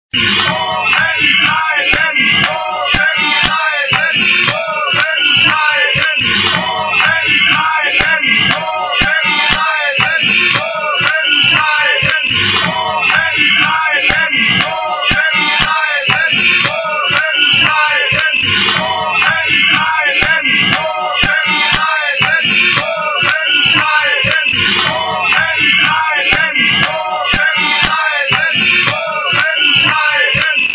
Oben-Bleiben Klingelton Version 1